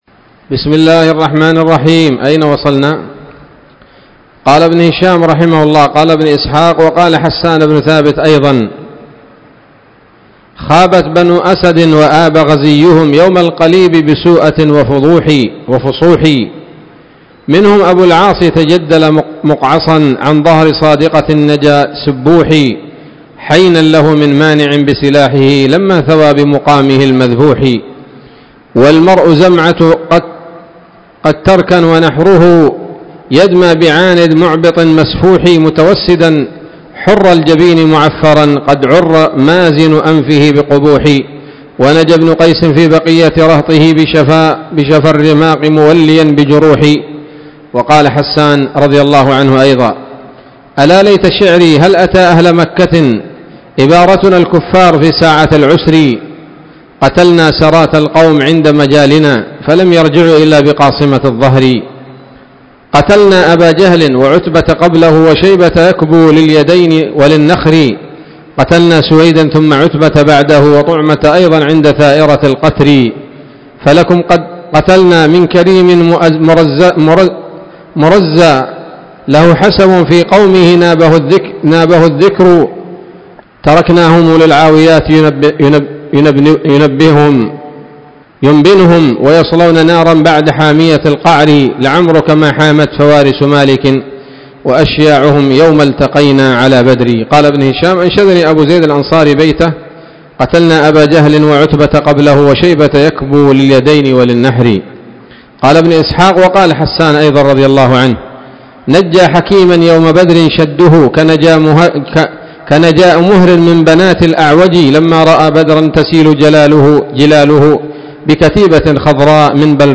الدرس الثالث والأربعون بعد المائة من التعليق على كتاب السيرة النبوية لابن هشام